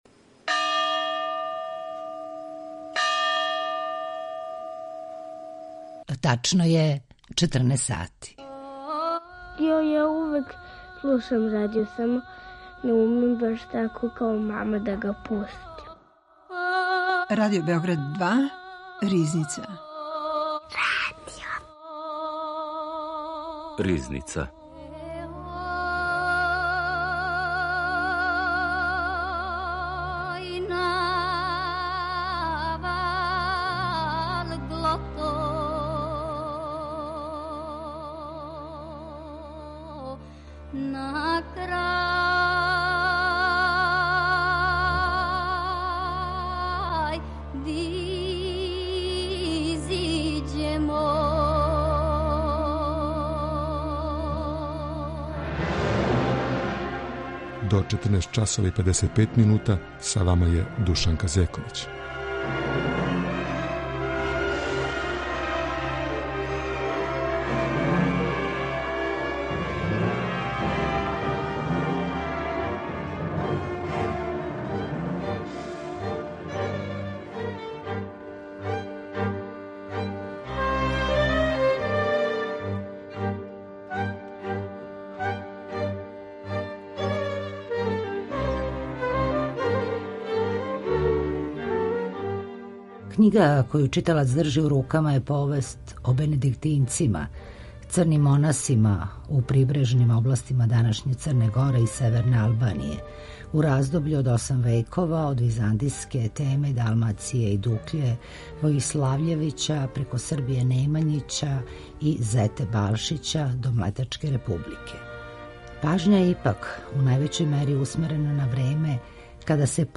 Гошћа историчарка